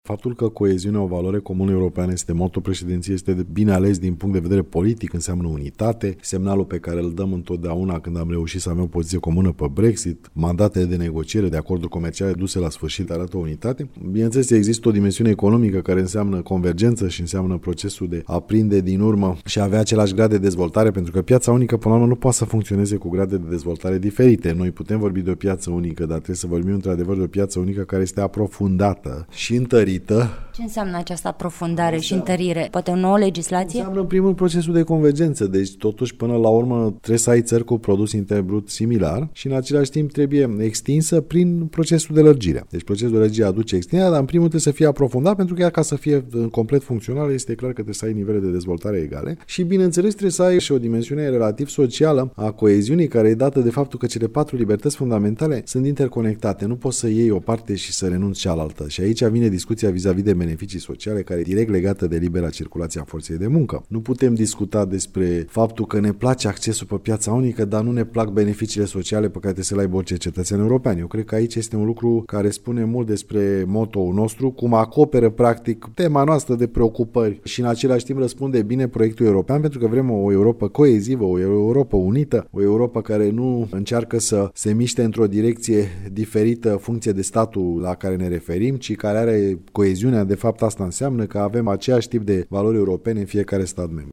Interviu cu George Ciamba (audio) - Radio România Oltenia-Craiova